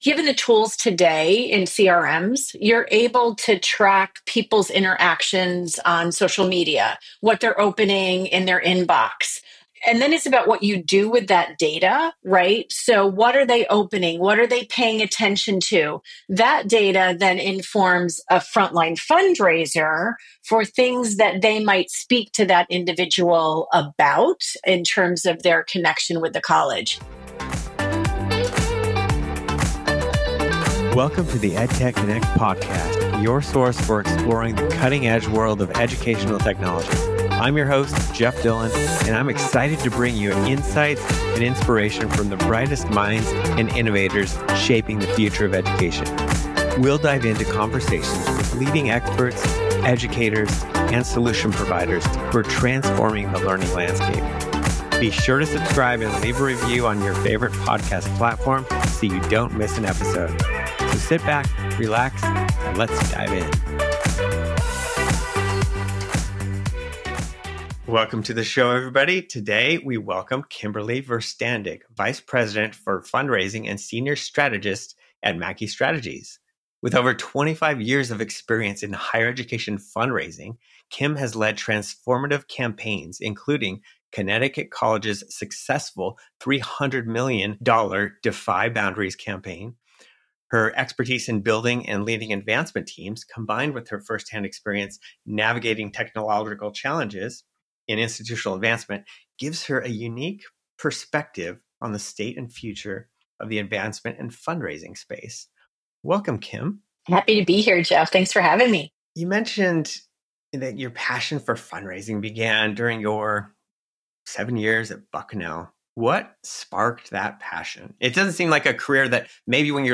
Each episode features interviews with leading experts, educators, technologists and solution providers, who share their insights on how technology can be used to improve student engagement, enhance learning outcomes, and transform the educational experience.